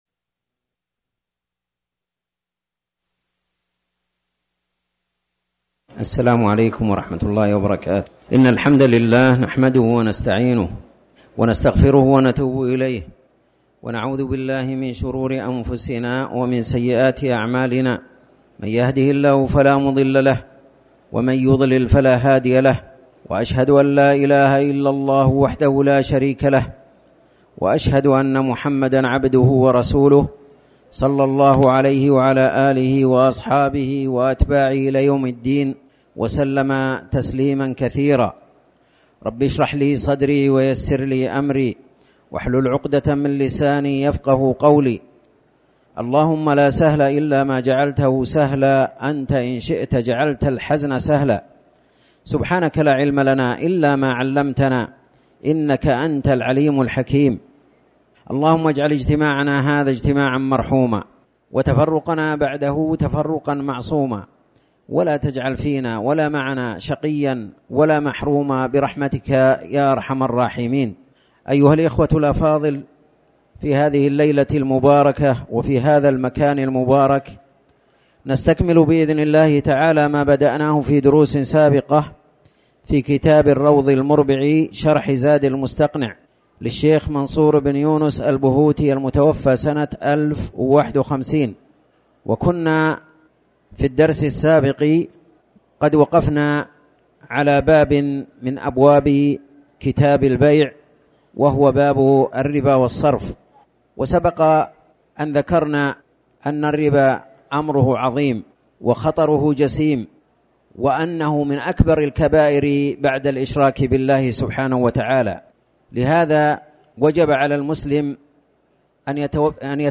تاريخ النشر ١٨ ربيع الثاني ١٤٤٠ هـ المكان: المسجد الحرام الشيخ